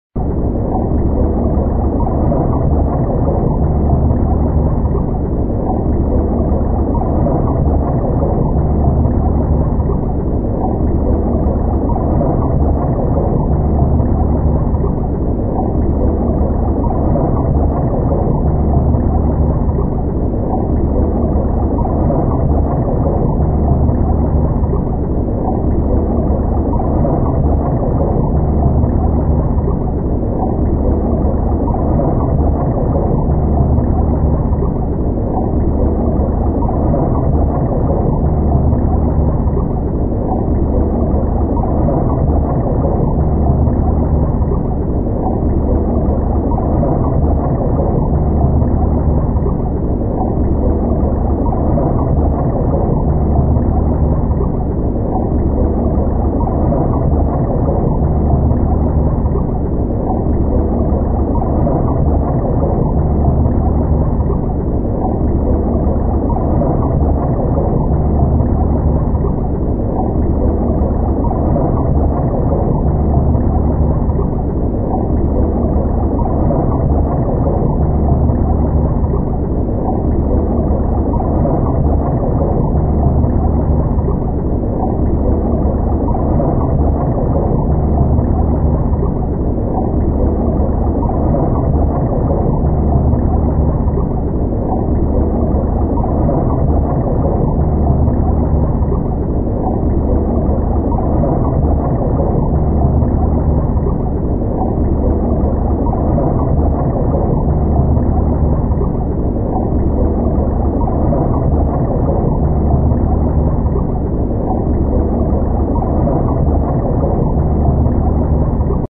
دانلود صدای زیر آب از ساعد نیوز با لینک مستقیم و کیفیت بالا
جلوه های صوتی
برچسب: دانلود آهنگ های افکت صوتی طبیعت و محیط